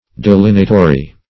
Search Result for " delineatory" : The Collaborative International Dictionary of English v.0.48: Delineatory \De*lin"e*a*to*ry\, a. That delineates; descriptive; drawing the outline; delineating.